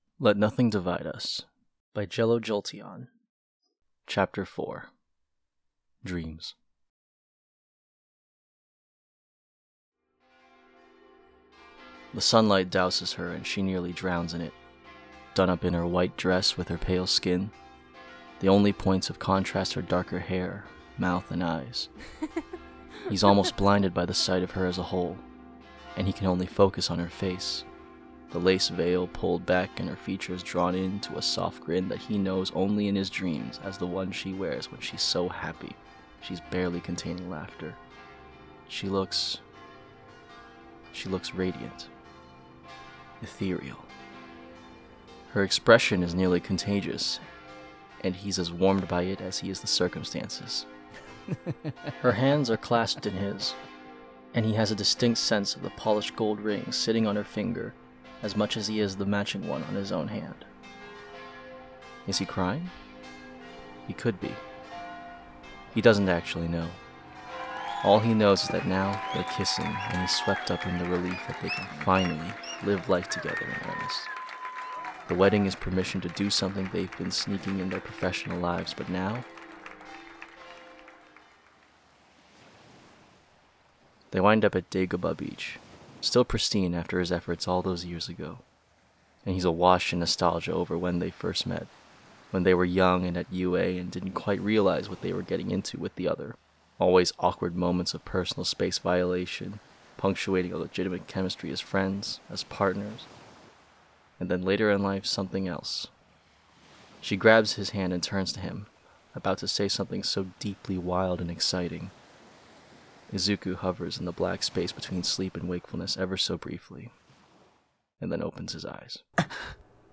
Let Nothing Divide Us : Chapter 4 - Dreams | Podfic
" Stirring in a cup of tea 1 " by Anti-HeroAnnie This sound is licensed under CC BY-NC 4.0 .